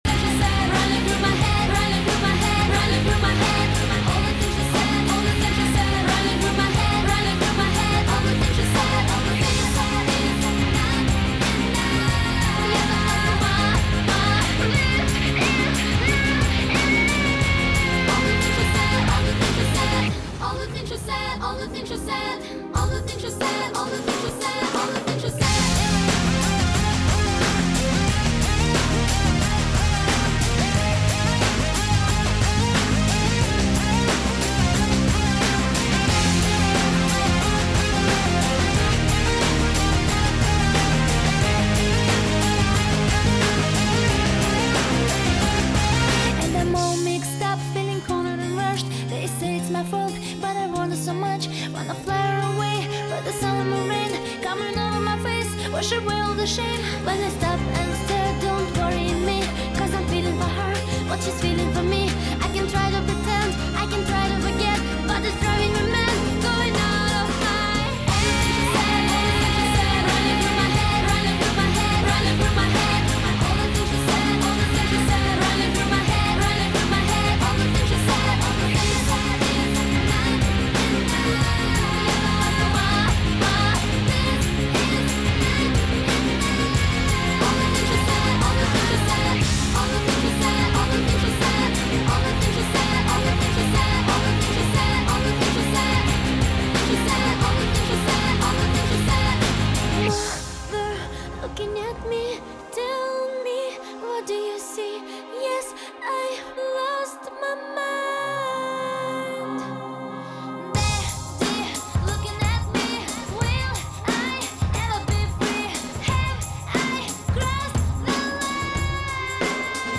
some music some chatting - the convent - KUSF